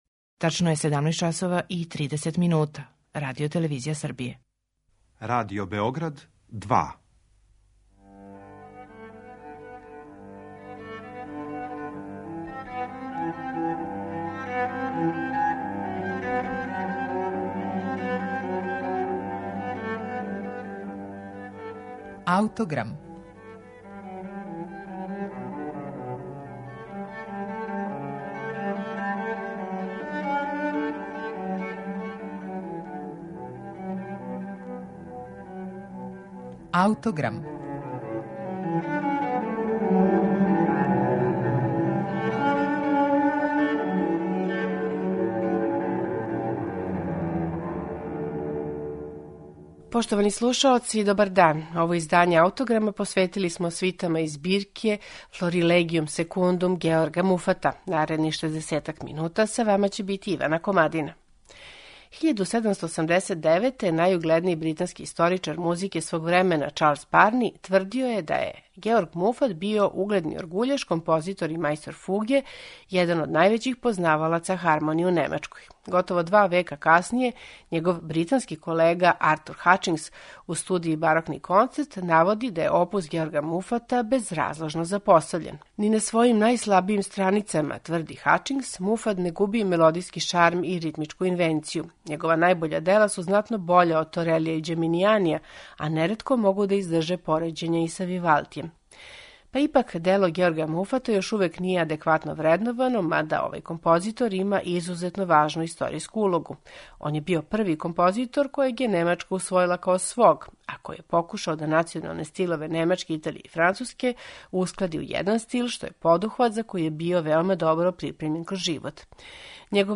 У обе збирке „Florigendum" обједињене су свите писане у стилу француског балета и праћене изузетно детаљним упутствима аутора о извођачкој пракси. У данашњем Аутограму представићемо две свите из ове Муфатове збирке: „Indisolubilis аmititia" (Неуништиво пријатељство) и „Nobilis Јuventus" (Племићка младеж). На оригиналним инструментима Муфатовог доба изводи их ансамбл „Armonico tributo"